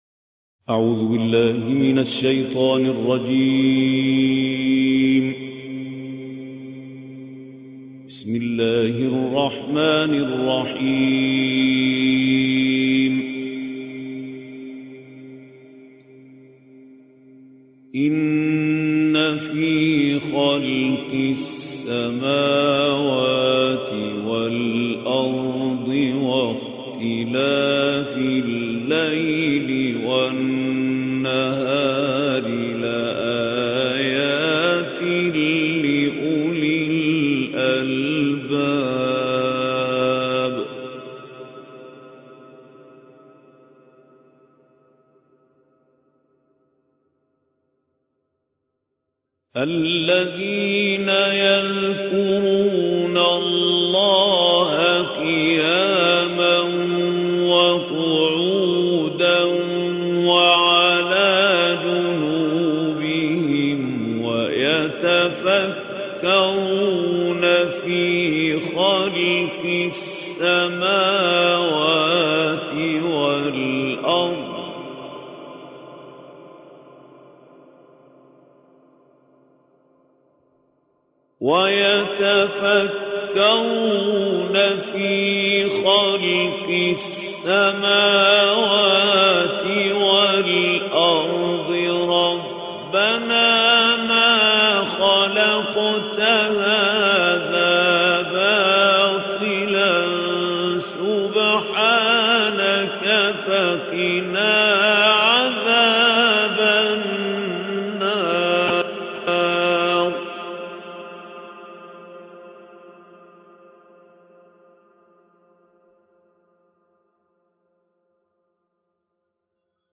Reciter Mahmoud Khaleel El Hussary